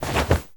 foley_jump_movement_throw_01.wav